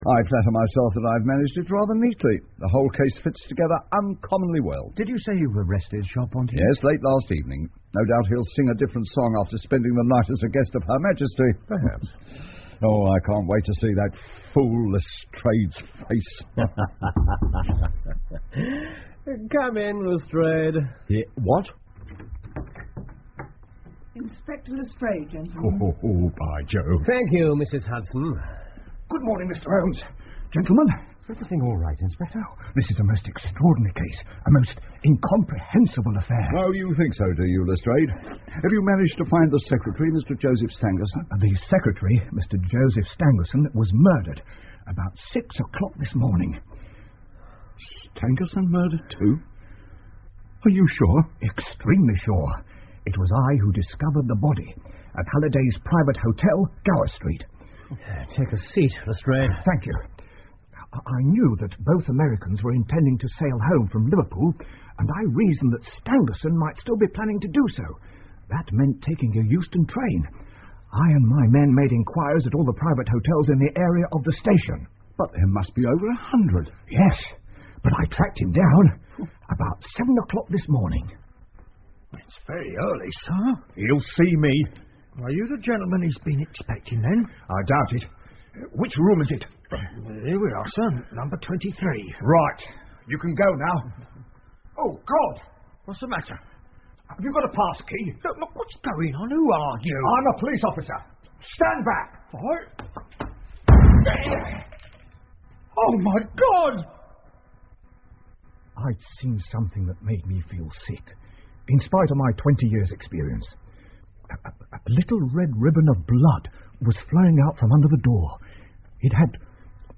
福尔摩斯广播剧 A Study In Scarlet 血字的研究 14 听力文件下载—在线英语听力室